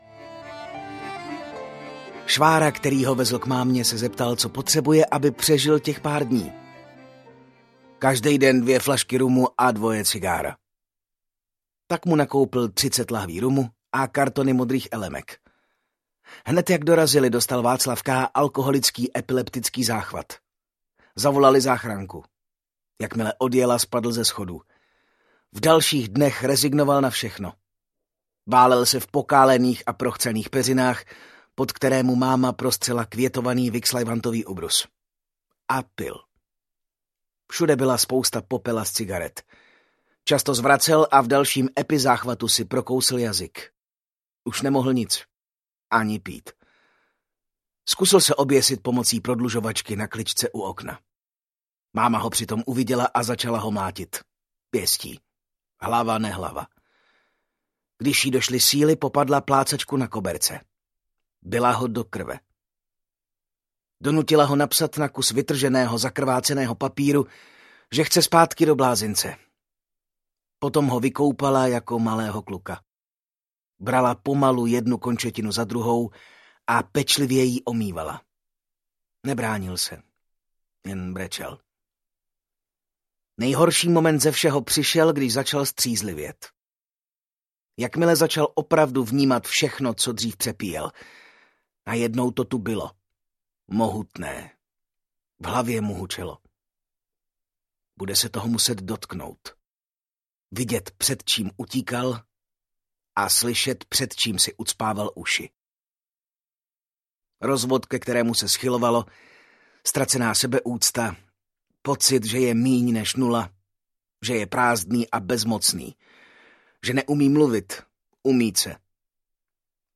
Úsměvy smutných mužů audiokniha
Ukázka z knihy